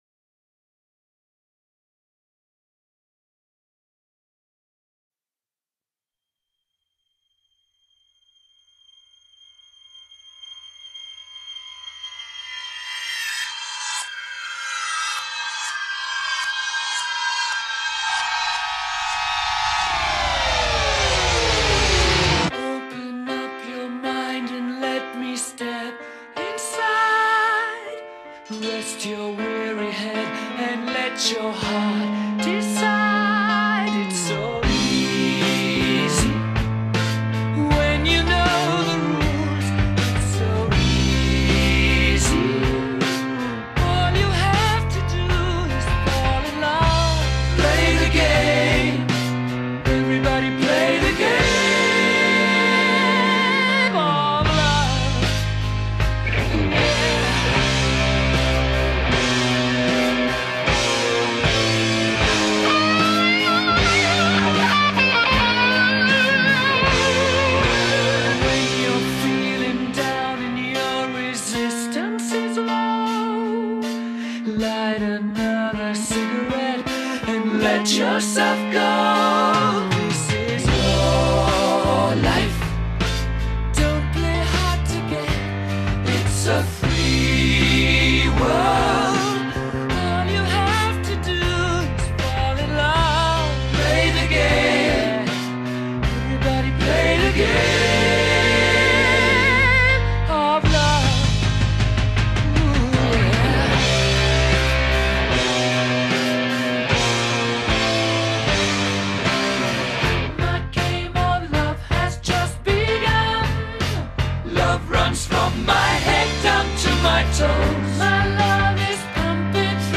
Rock, Funk Rock, Pop Rock